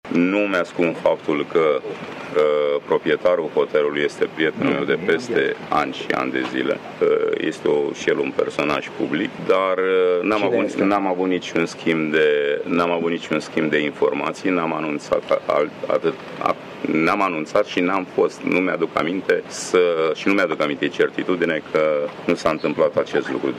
Piedone susține că Rușanu îi este prieten de ani de zile și că nu a avut niciun schimb de informații cu acesta. ”Nu am anunțat și nu-mi aduc aminte că nu s-a întâmplat acest lucru”, susține Piedone.